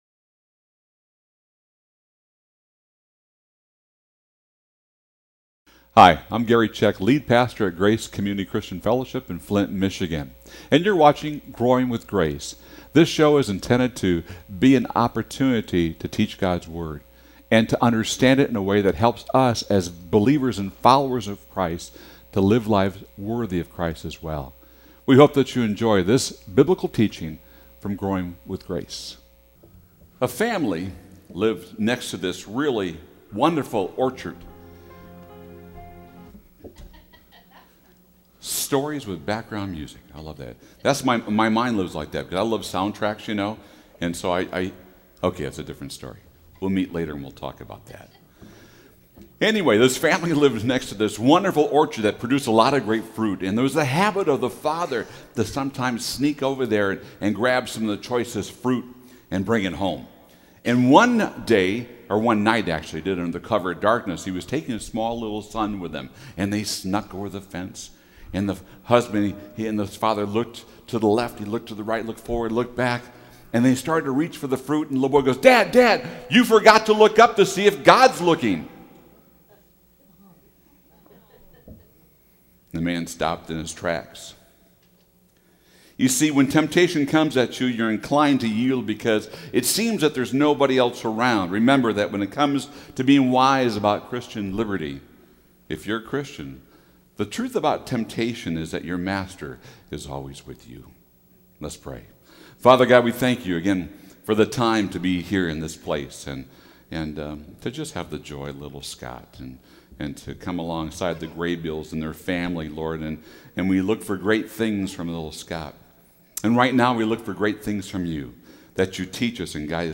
An Expository Study Through 1st Corinthians - 1st Corinthians 10:1-13